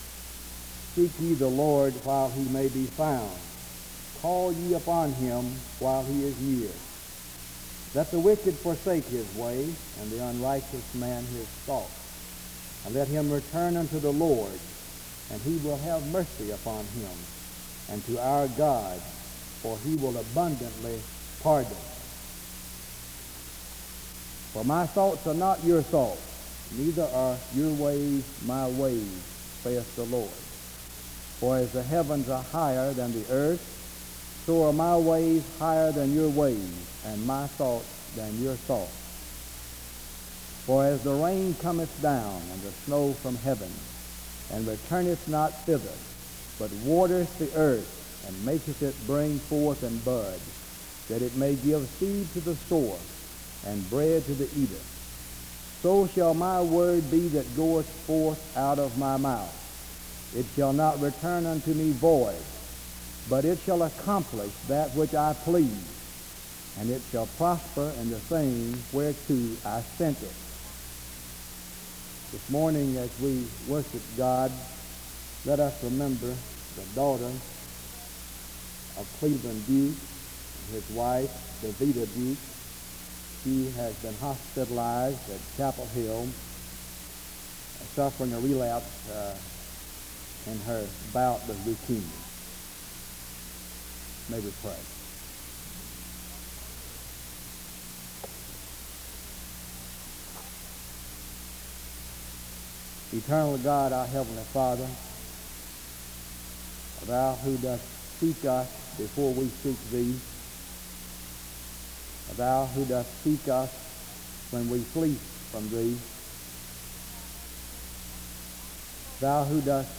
The service starts with an opening scripture reading from 0:00-1:35. A prayer is offered from 1:43-2:49. A responsive reading takes place from 2:50-4:37.